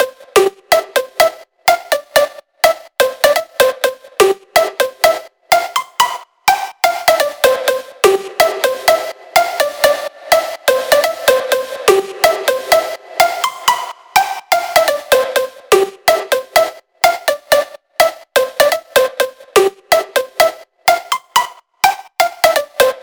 Нарисовал первый попавшийся под руку плак и обработал экспандером.